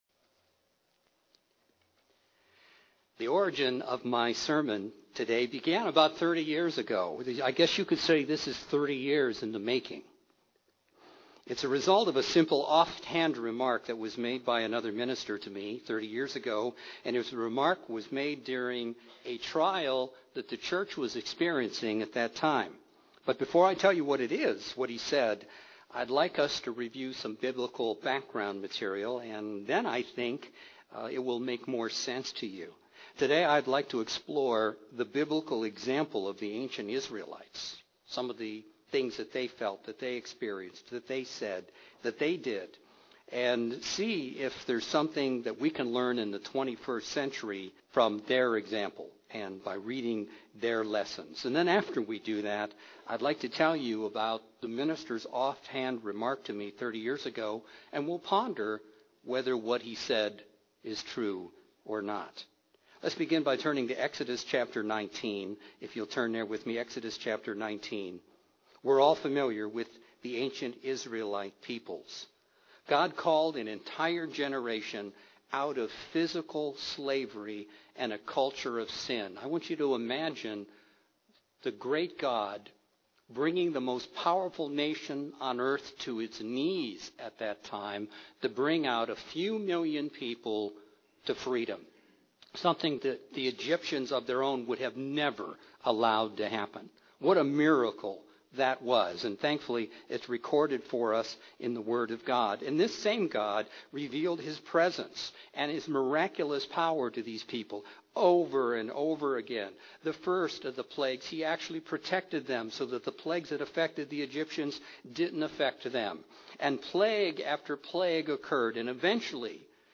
Recent challenges in the Church has led many to wonder if our present generation will be the one that finishes the Biblical commission of preaching the gospel to the entire world. This sermon looks at the historical example of ancient Israel and candidly asks if there is a parallel for us today.